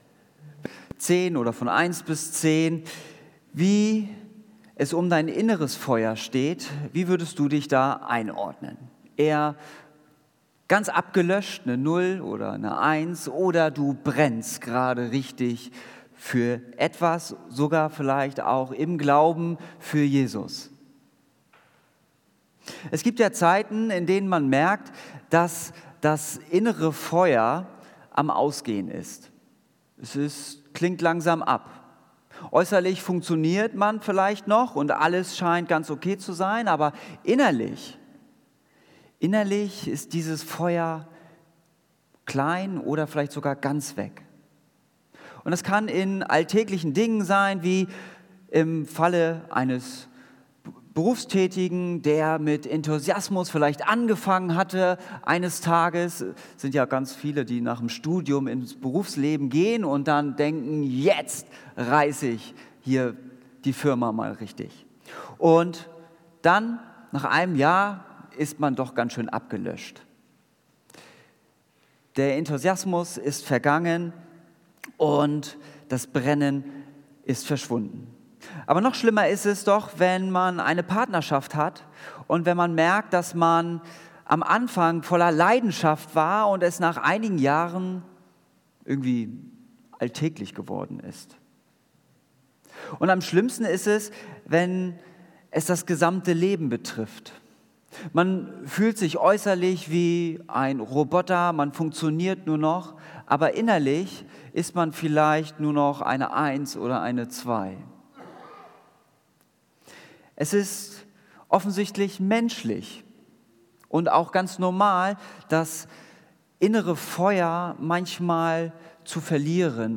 Predigt Zurück zur ersten Liebe